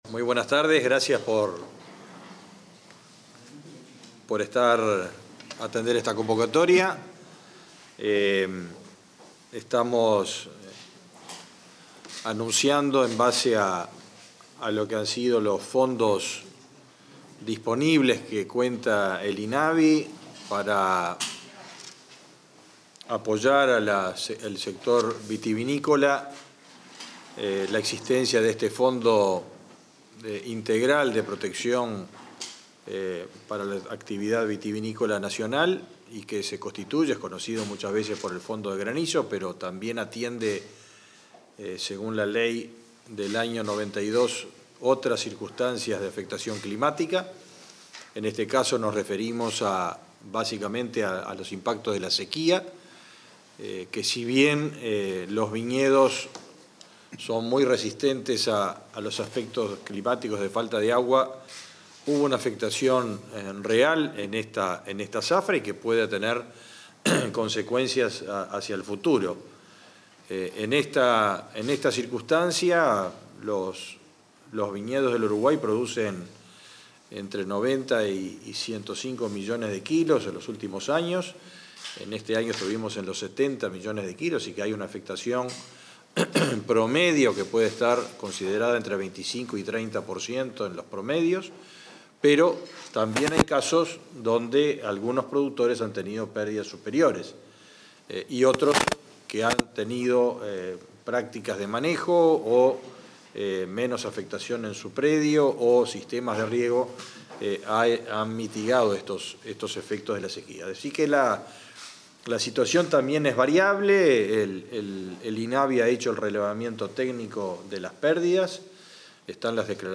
Conferencia de prensa para informar acerca del plan de apoyo a productores vitivinícolas afectados por la crisis hídrica
Conferencia de prensa para informar acerca del plan de apoyo a productores vitivinícolas afectados por la crisis hídrica 26/07/2023 Compartir Facebook X Copiar enlace WhatsApp LinkedIn Con la presencia del ministro de Ganadería, Agricultura y Pesca, Fernando Mattos, y el presidente del Instituto Nacional de Vitivinicultura, Ricardo Cabrera, se realizó, este 26 de julio, una conferencia de prensa, para brindar detalles acerca del plan de apoyo a productores vitivinícolas afectados por el déficit hídrico durante la cosecha 2023.